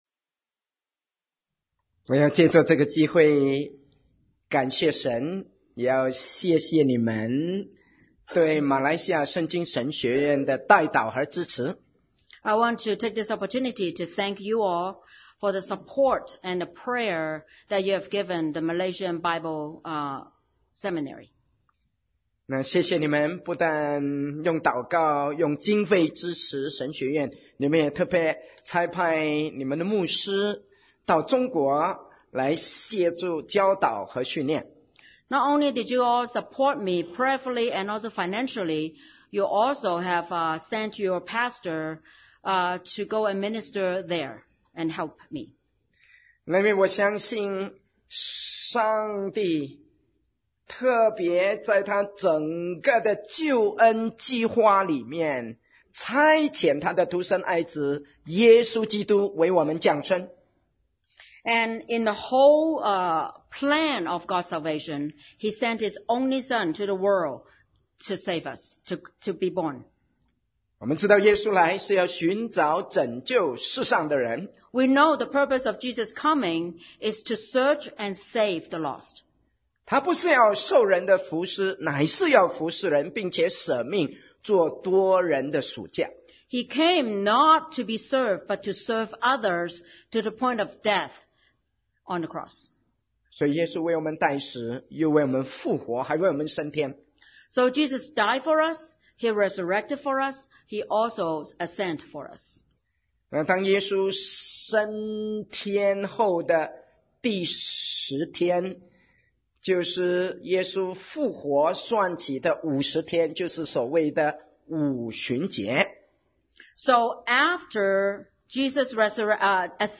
Sermon 2017-12-10 The Common Commission of the Church and Seminary – To know God more